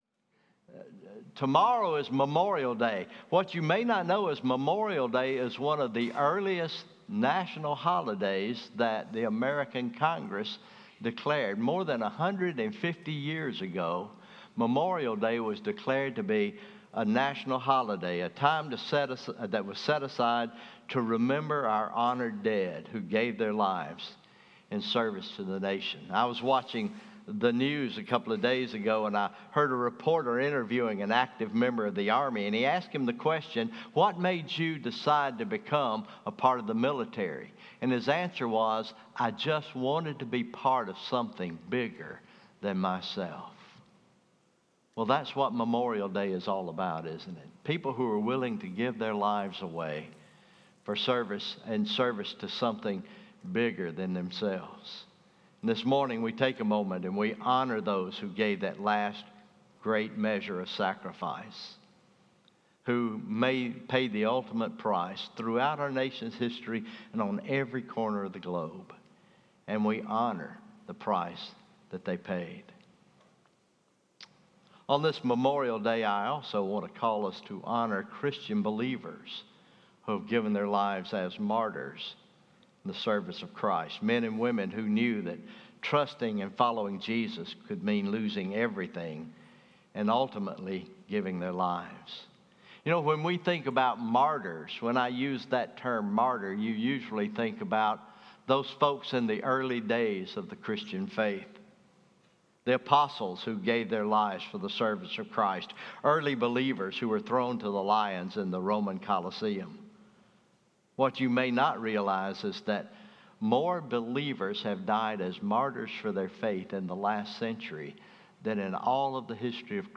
May 26, 2024 Morning Worship, Memorial Day Weekend